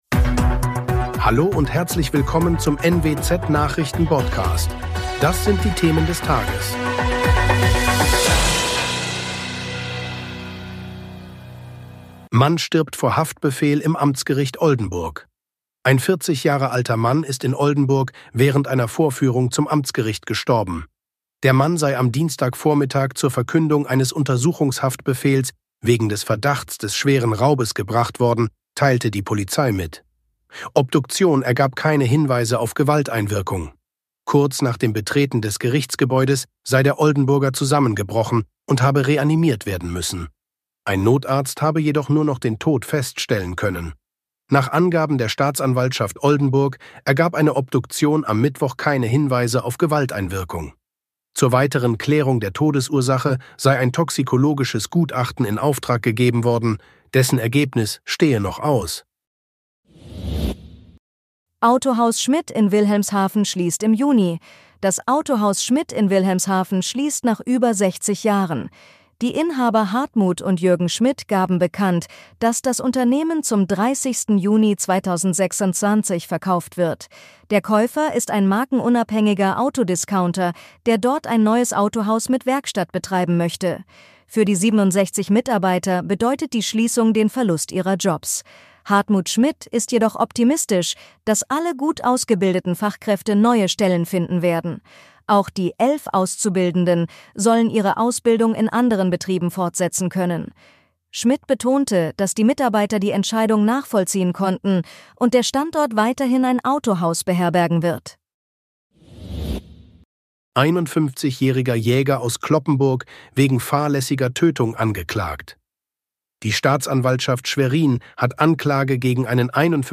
Genres: Daily News, News